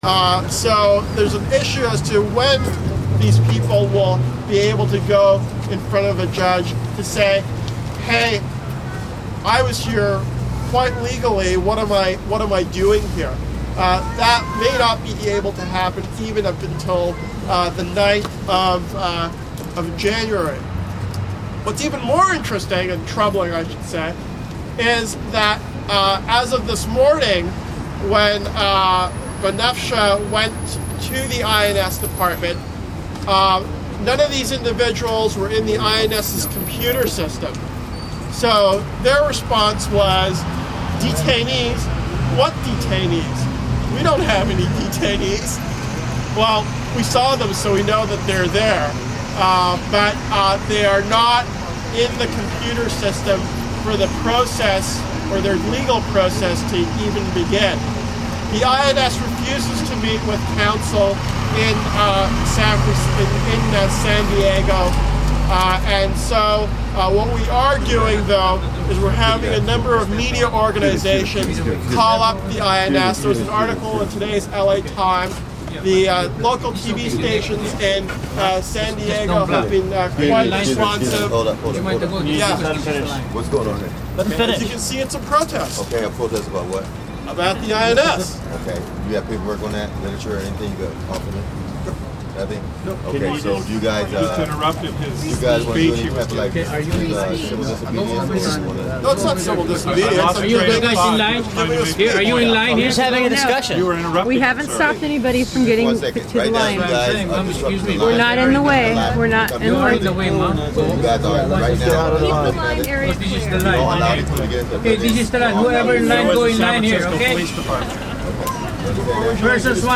speech3.mp3